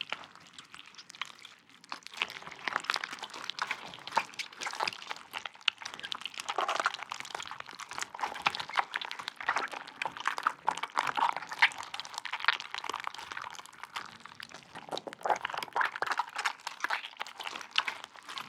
Sfx_creature_seamonkeybaby_idle_squishy_loop_01.ogg